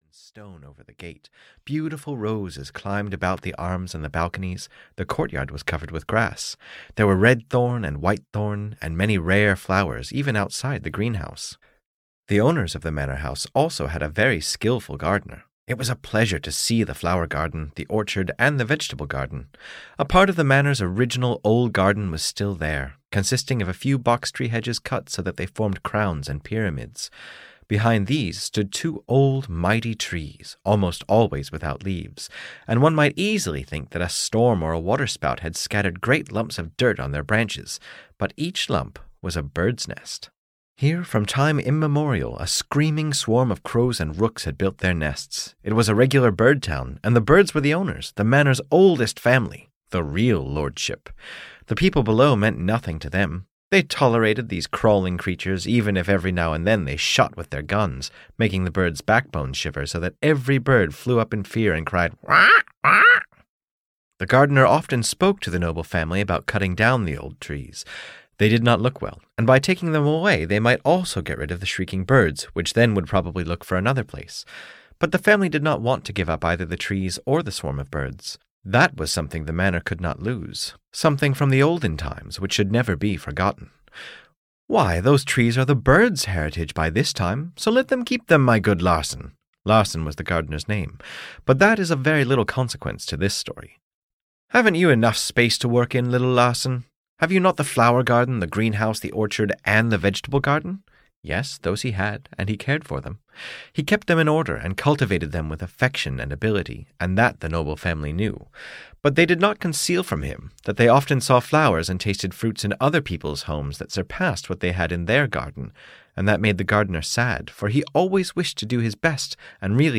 The Gardener and the Noble Family (EN) audiokniha
Ukázka z knihy